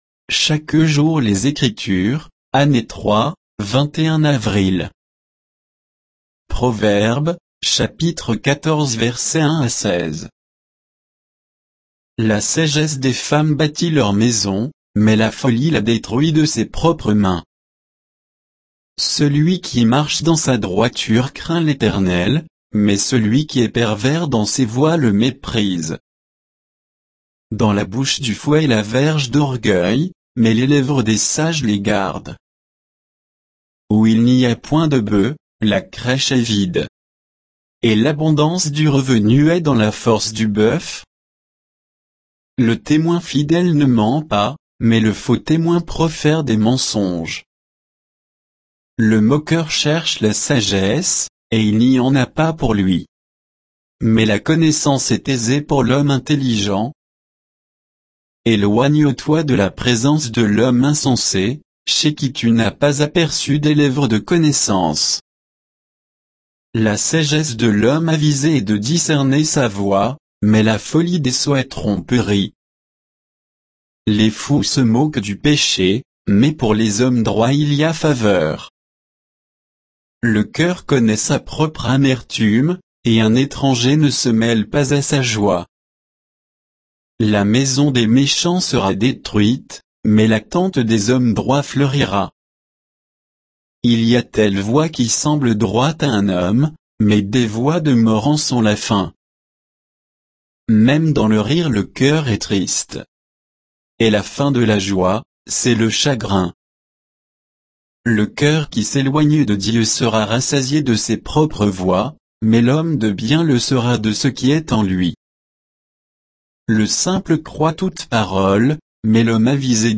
Méditation quoditienne de Chaque jour les Écritures sur Proverbes 14, 1 à 16